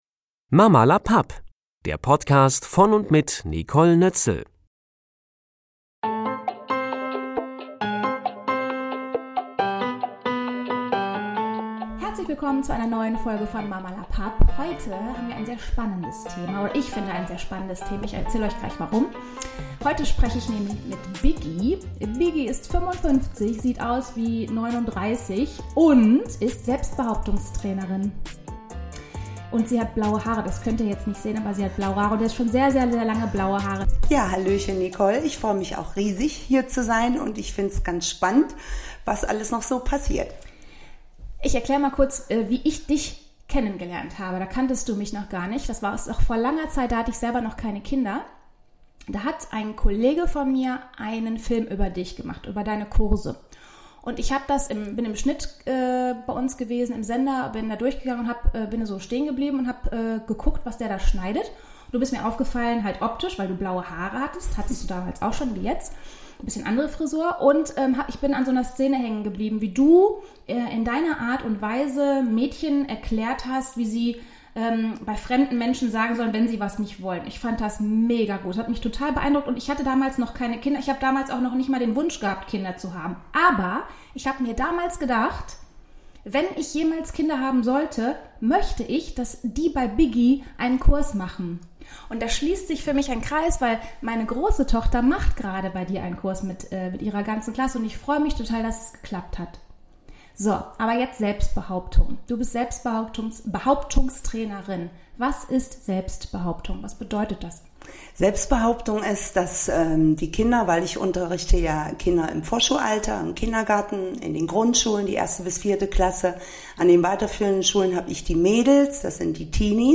Davon erzählt sie mir in unserer heutigen Podcast-Folge.